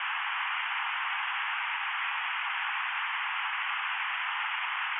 Noise
Noise sounds like whistling wind, or crinkling tin foil. Here are a couple of examples of how noise signals can sound.